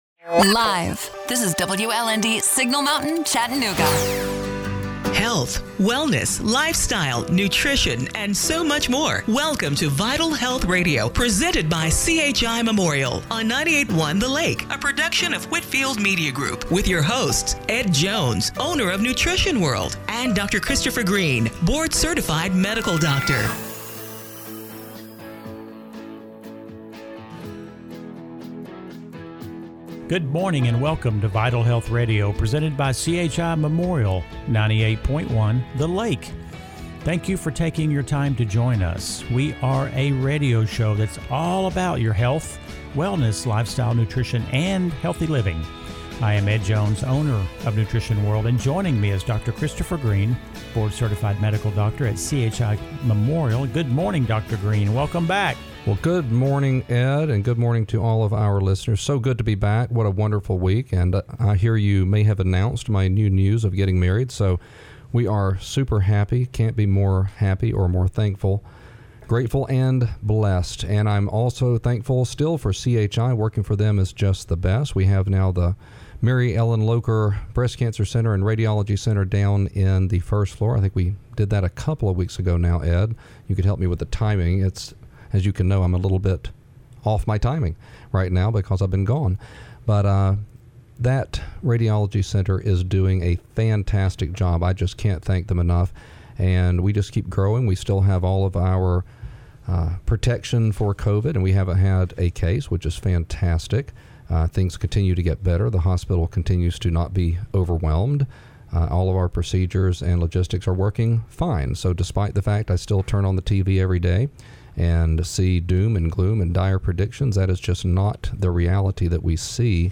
August 23, 2020 – Radio Show - Vital Health Radio